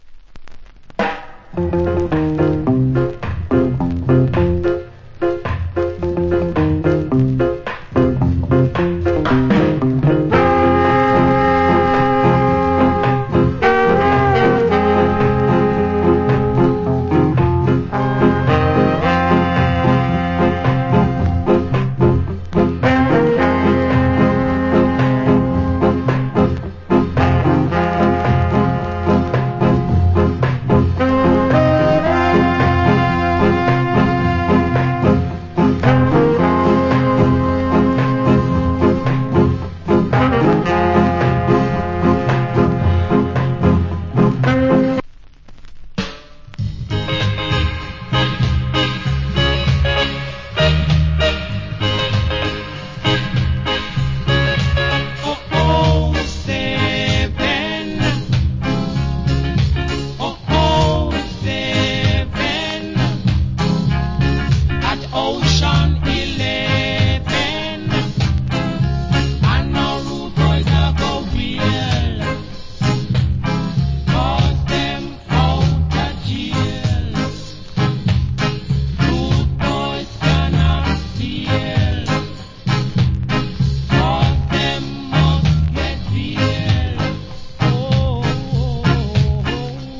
Good Inst.